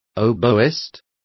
Complete with pronunciation of the translation of oboist.